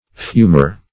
Search Result for " fumer" : The Collaborative International Dictionary of English v.0.48: Fumer \Fum"er\, n. 1.